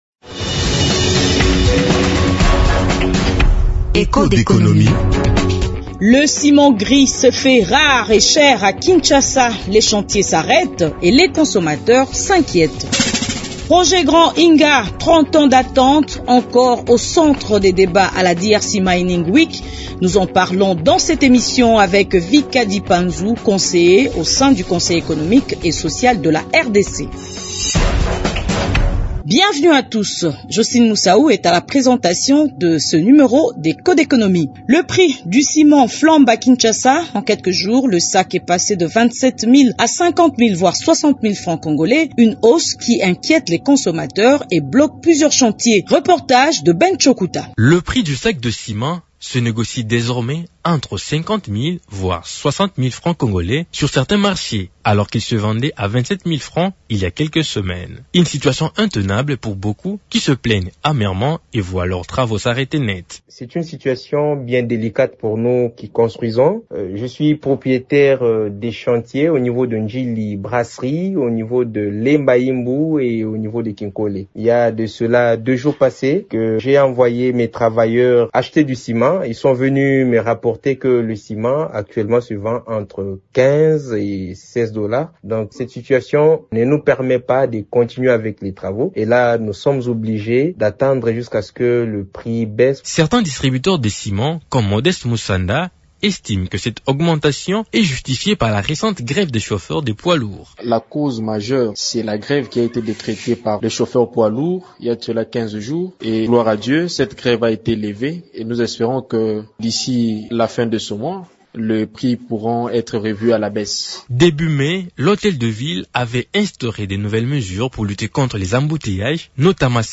-Voici les autres sujets du magazine Echos d’économie du jeudi 12 juin 2025 :